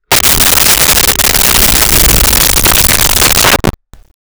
Creature Snarl 01
Creature Snarl 01.wav